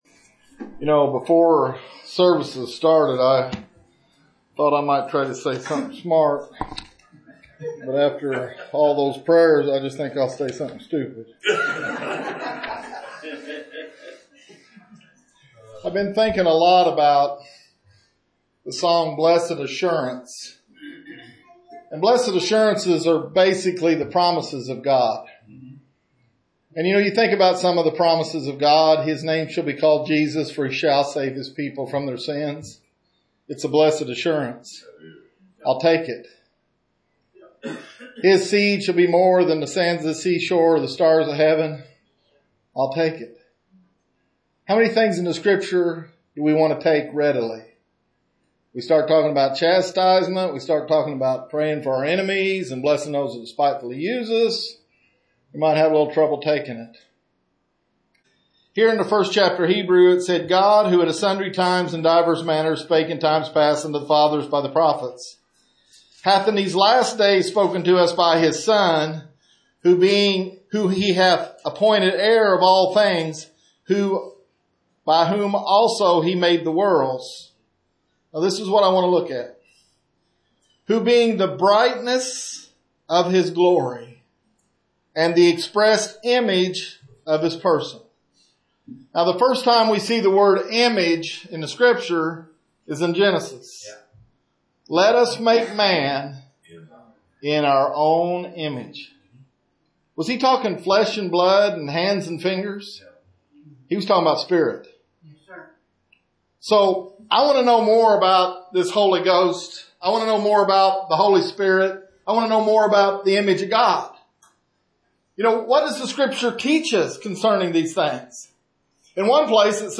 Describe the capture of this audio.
Sermons preached in other churches • Page 5